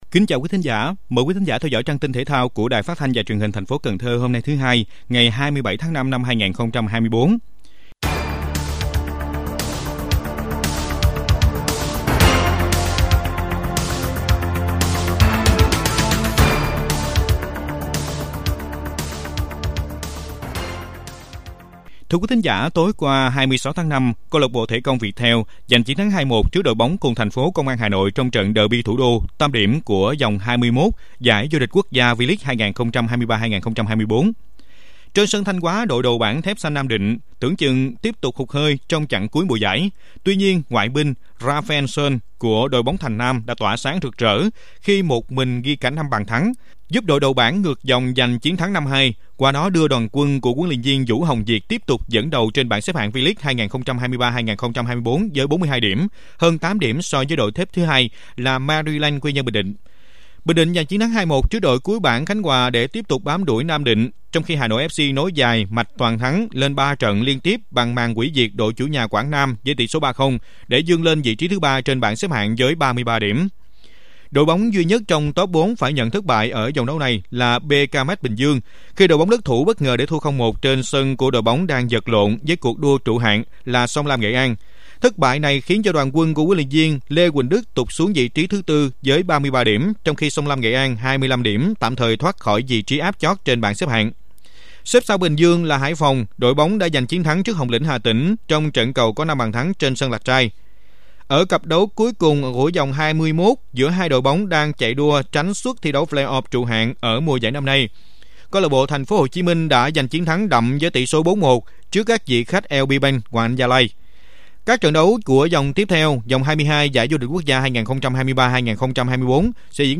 Bản tin thể thao 27/5/2024